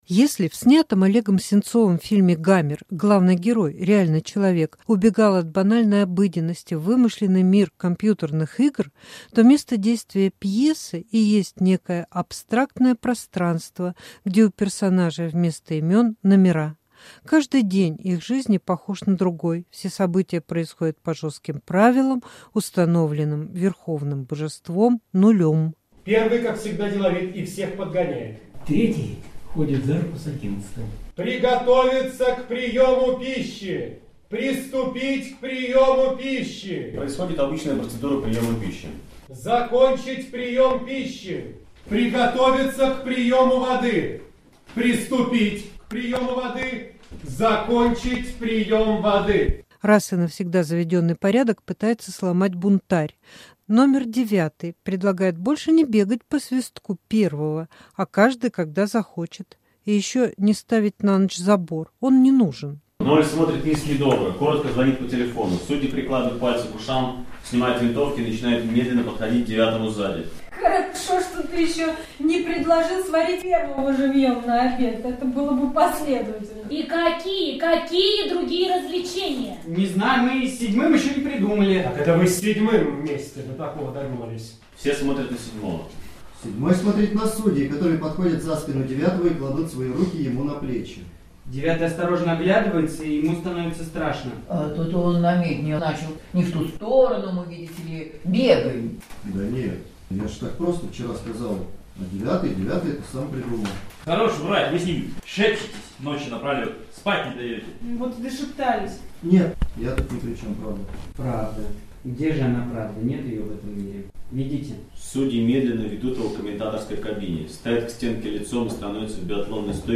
В Театре.doc состоялась публичная читка написанной Олегом Сенцовым пьесы "Номера"